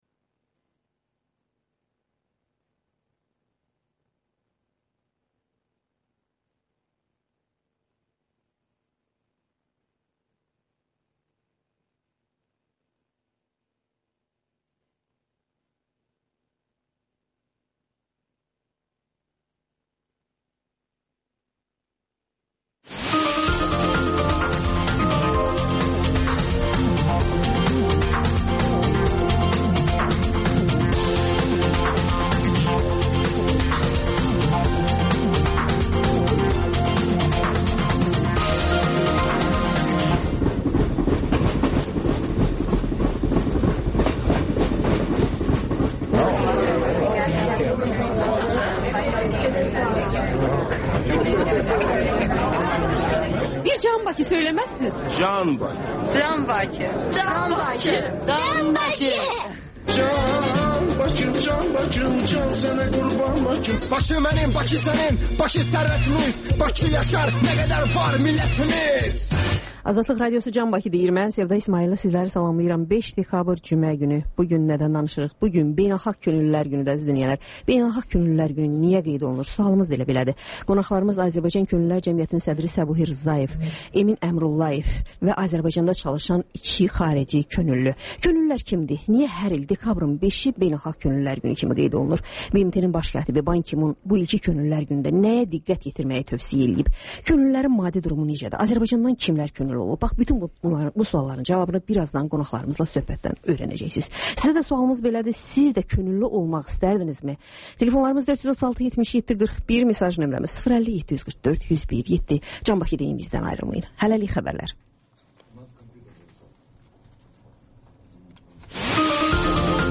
Xəbərlər, sonra CAN BAKI verilişi: Bakının ictimai və mədəni yaşamı, düşüncə və əyləncə həyatı…(Təkrarı saat 14:00-da)